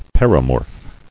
[per·i·morph]